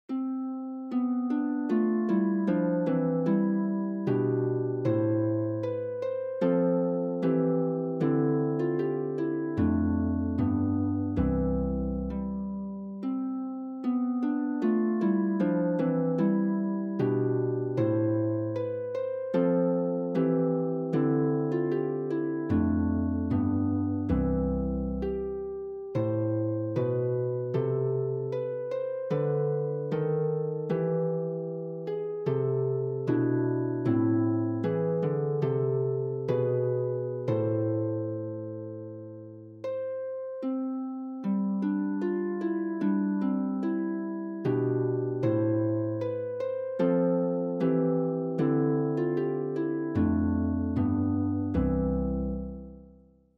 The Minstrel Boy | Free Easy Celtic Harp Sheet Music
Free printable sheet music for The Minstrel Boy by Thomas Moore for Easy Harp Solo.
The Minstrel Boy is an Irish patriotic song that some believe Moore composed in remembrance of friends that died in the Irish Rebellion of 1798.
minstrel_boy_harp.mp3